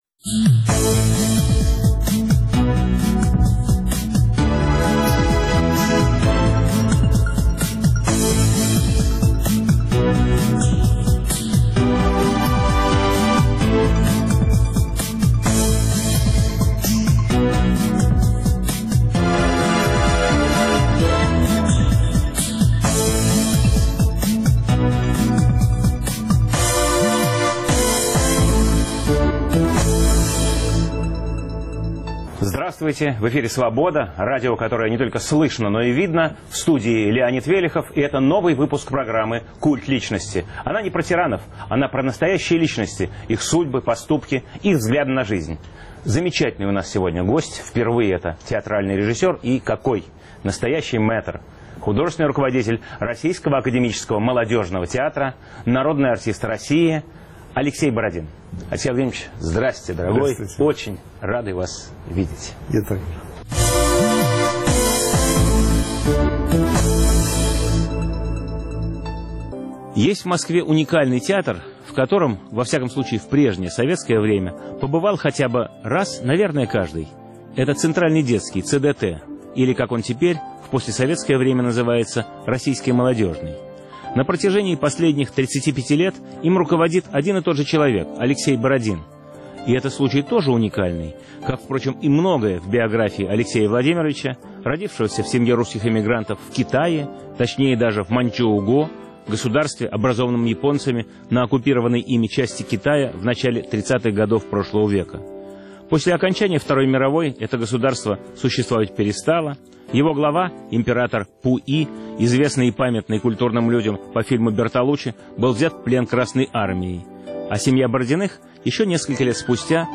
Новый выпуск программы о настоящих личностях, их судьбах, поступках и взглядах на жизнь. В студии замечательный режиссер, художественный руководитель театра РАМТ Алексей Бородин.